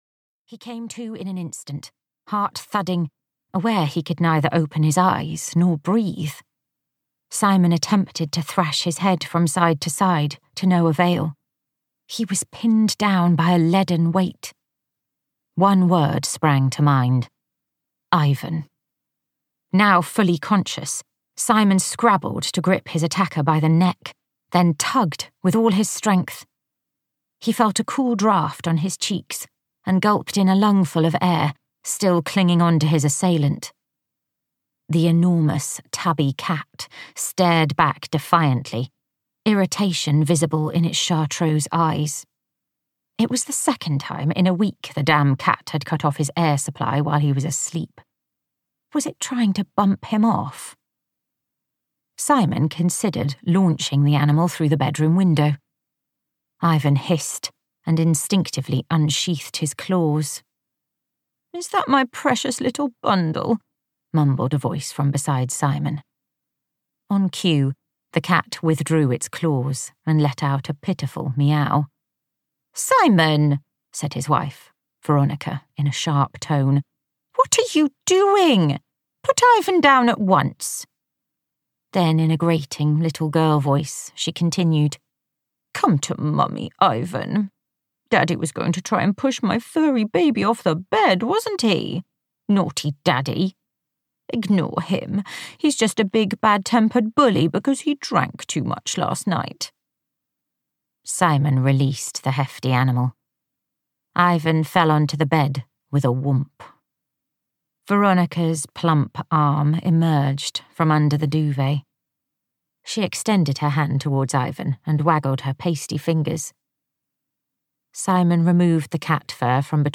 Life Swap (EN) audiokniha
Ukázka z knihy